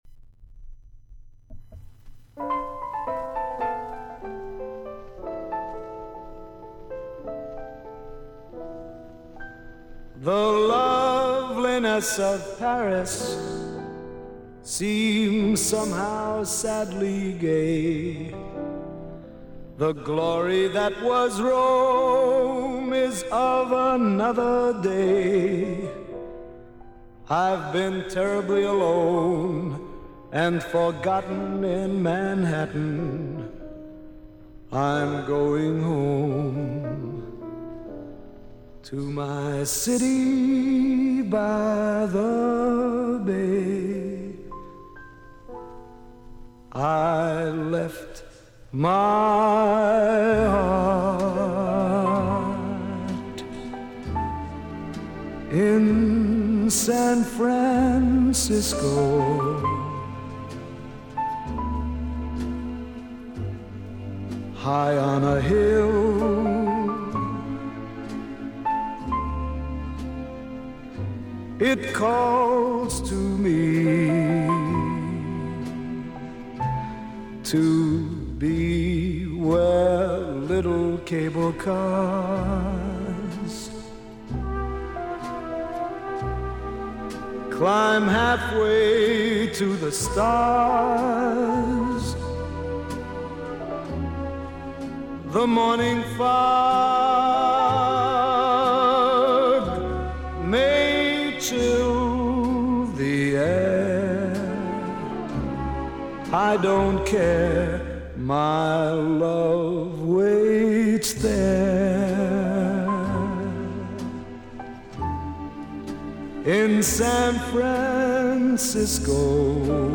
Жанры Свинг
Поп-музыка
Джаз